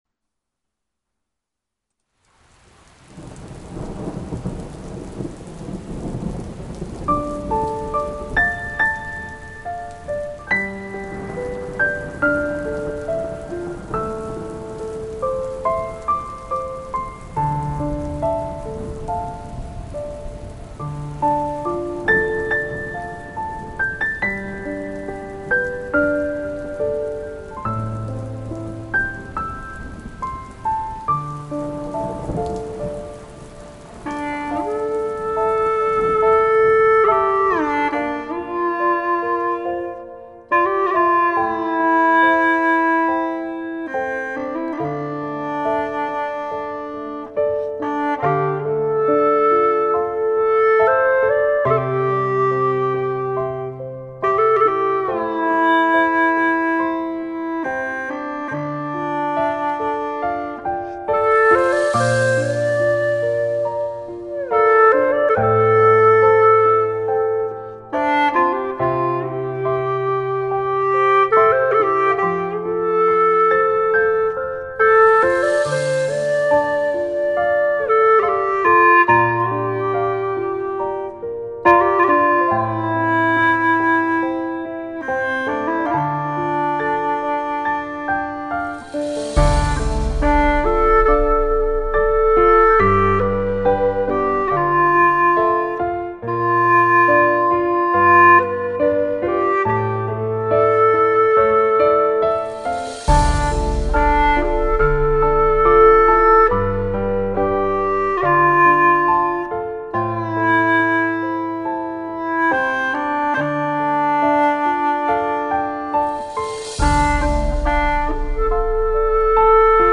调式 : F 曲类 : 古风
老师吹出了意境,真好听！